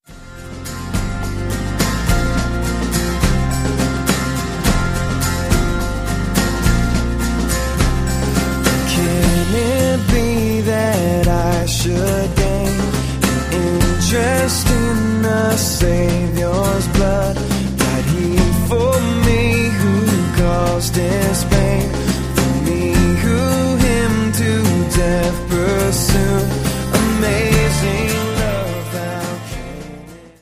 • Sachgebiet: Praise & Worship